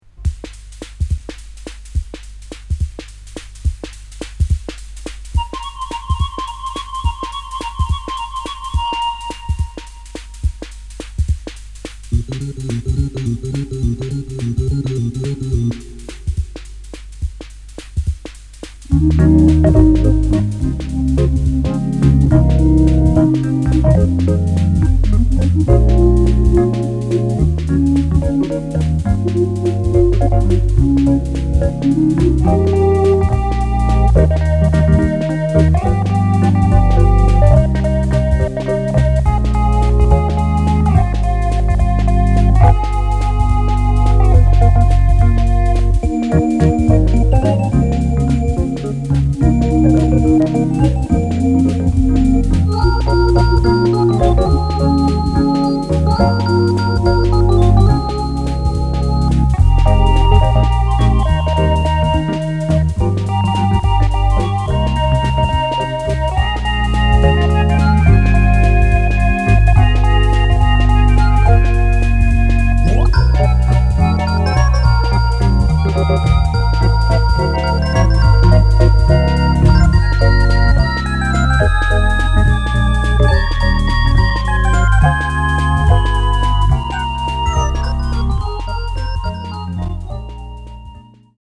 星空をひろげたような夢見心地の煌めきを放つムーグラウンジずらり。
電子音　ライブラリー　ラウンジ